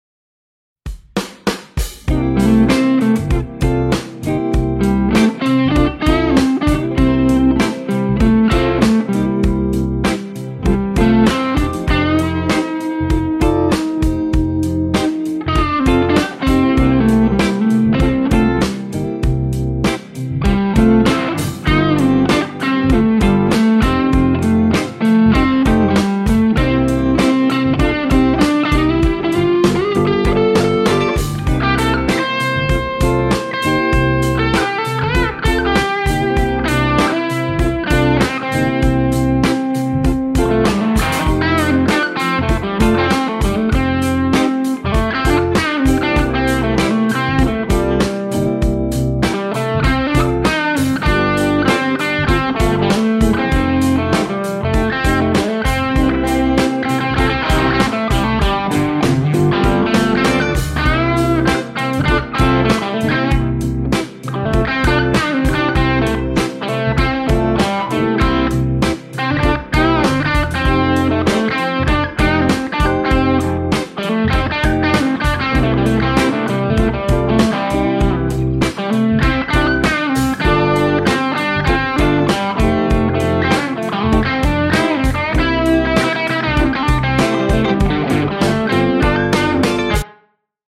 Aufgenommen mit Standard Tele Mexico mit Nocaster 51er Pickup, am Anfang Neck, dann Bridge Ep-Booster, Nobels ODR-1, White Whale Federhall, alles die ganze Zeit an und unverändert Fender Vibro Champ 68er (Volume auf 5) Fryette PS Mikrofoniert habe ich wieder mit Beyerdynamic M260N, habe jedoch dies nicht verwendet. Daher das Line-Signal der PS genommen und in Two Notes ein IR von Ownhammer (Fender Vibroverb 115er) eingestellt.
Ein weitere Punkt war mehr Gain zu verwenden, was letztes Mal auch etwas in die Hose ging.